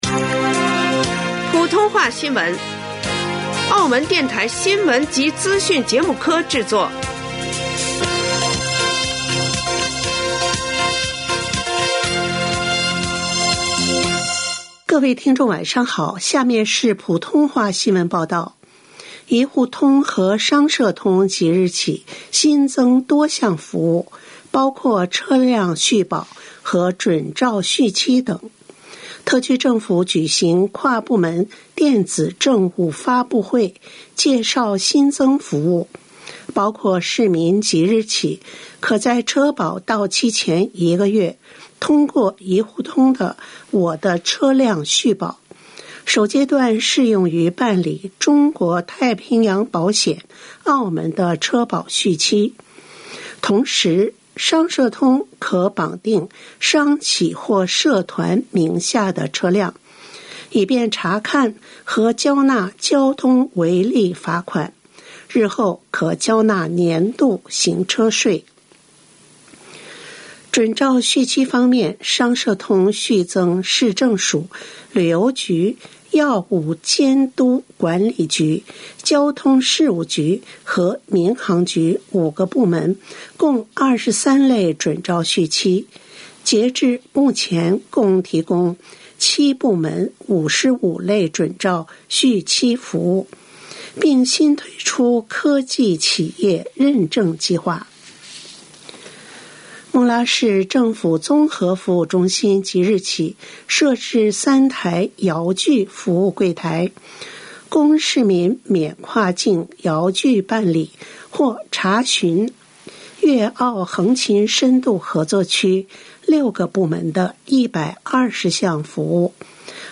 2025年11月04日 19:30 普通話新聞